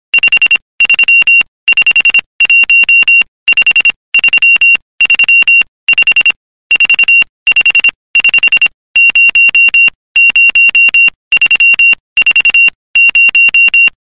Мне чот прям аж стыдно стало...((за 12 лет морзянку забыл(((